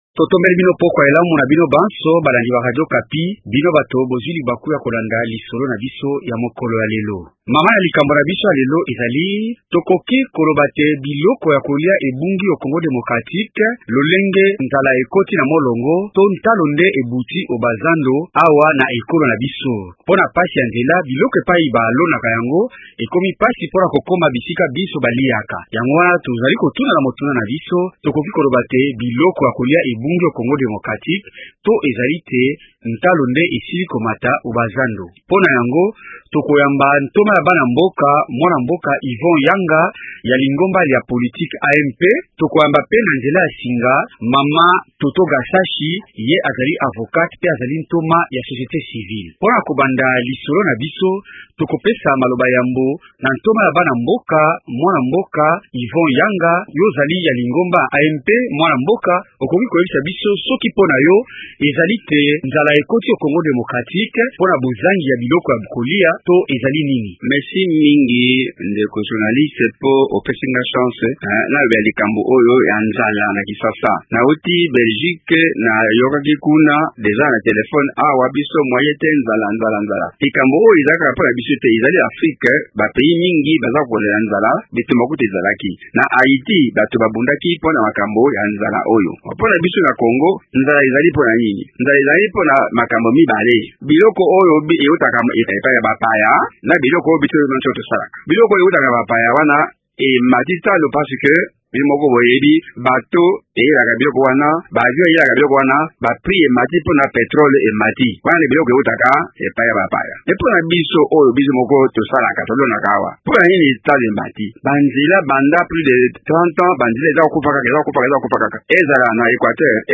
Bantoma ya AMP pe bantoma bya société civile bakopesa biyano po na motuna moye na lisolo na biso ya pokwa ya lelo.